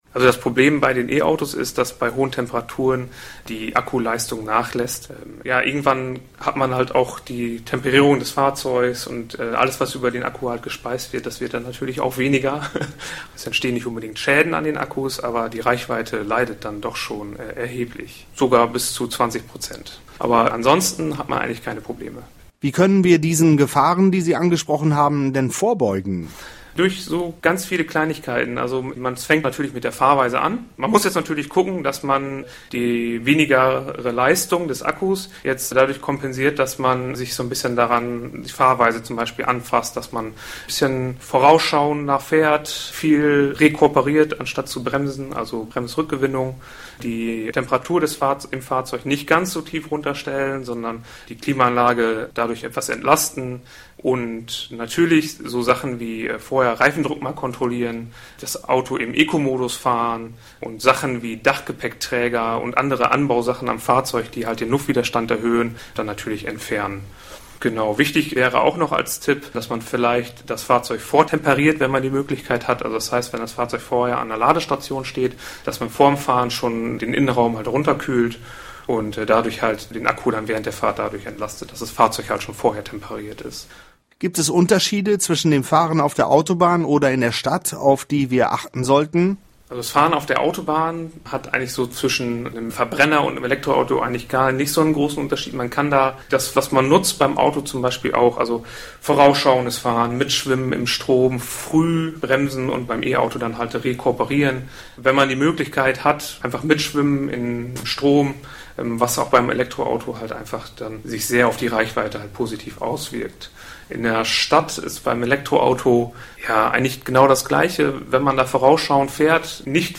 Bei starker Hitze wird der Akku geschwächt und die Reichweite verringert sich. Um kurz- und langfristige Schäden zu vermeiden, haben wir mit einem Experten über den richtigen Umgang mit E-Autos im Sommer gesprochen.